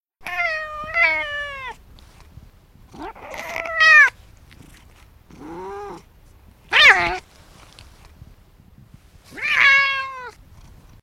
cat-sound